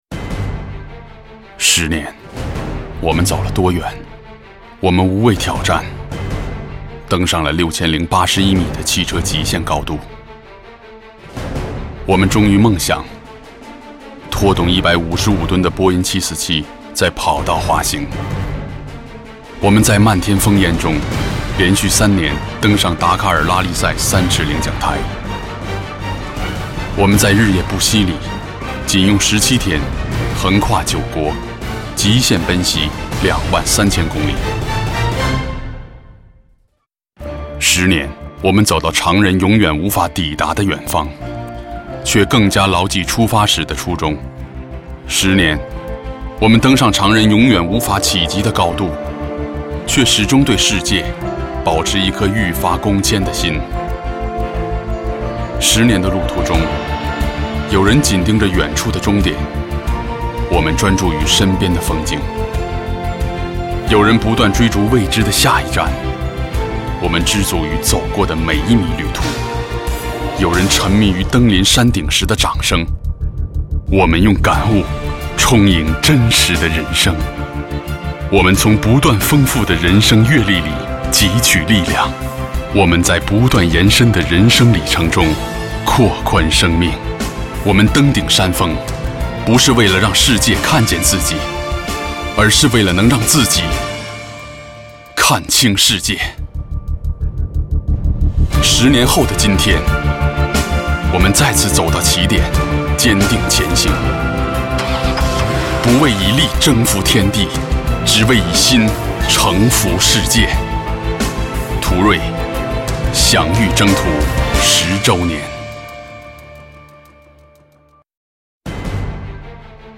• 男11 国语 男声 专题片 大众途锐十年_旁白内心力量 低沉|大气浑厚磁性|沉稳|娓娓道来